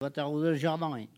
Elle provient de Bouin.
Catégorie Locution ( parler, expression, langue,... )